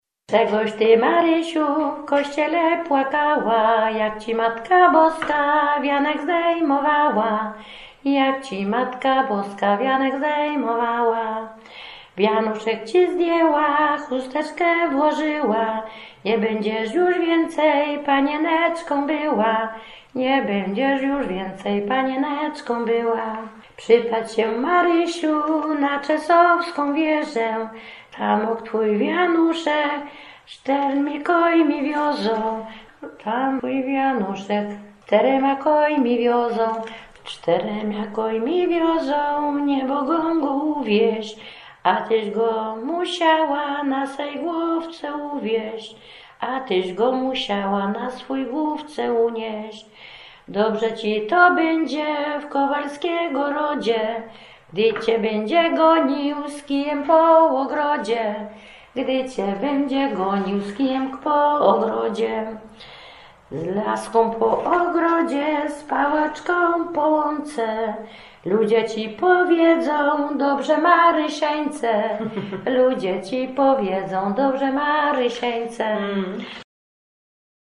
cenioną śpiewaczką ludową